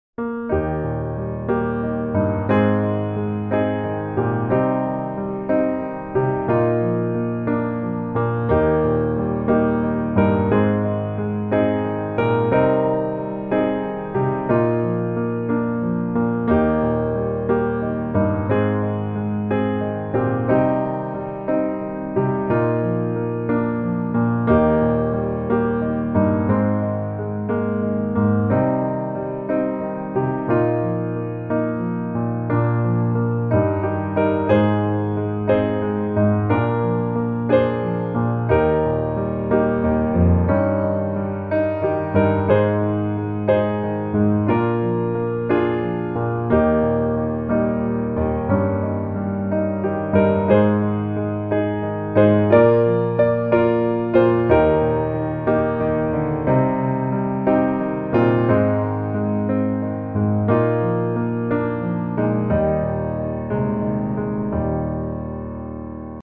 Eb Majeur